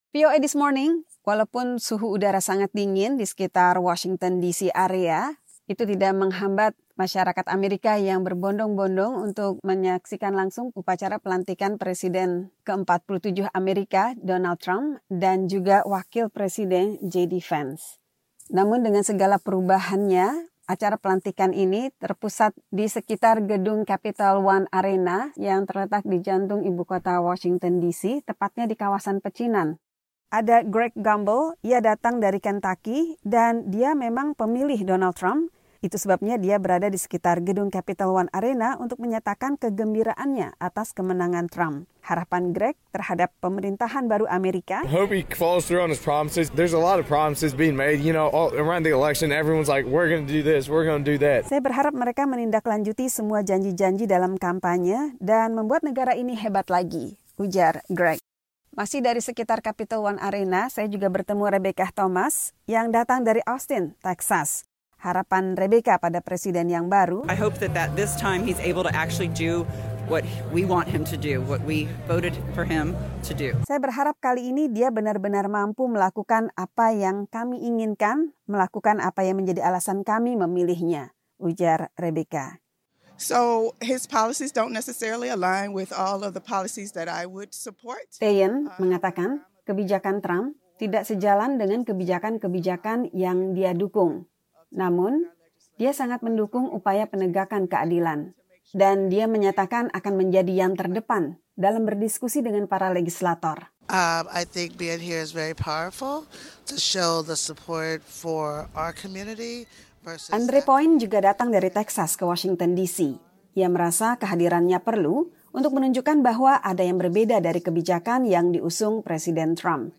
mewawancarai warga AS yang berada di sekitar Capital One Arena, Washington, DC tentang harapan mereka terhadap presiden ke-47 AS.